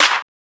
Tm8_Snare26.wav